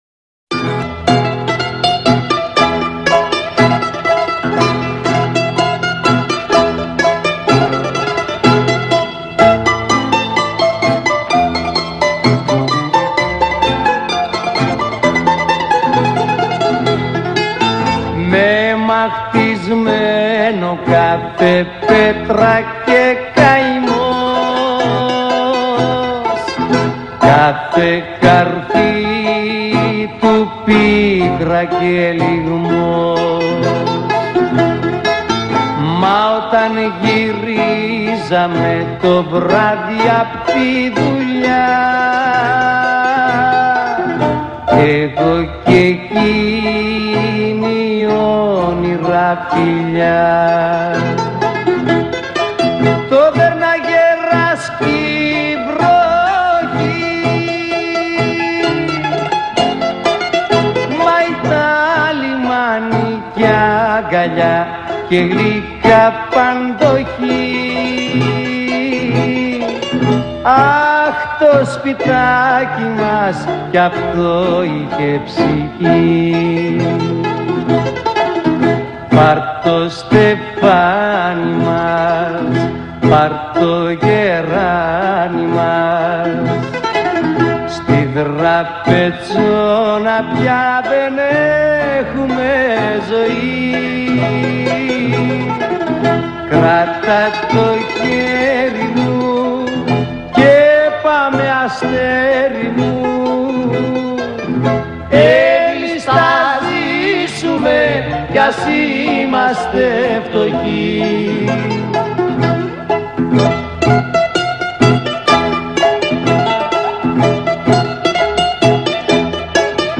Rembetika: Yunanistan’ın Blues’u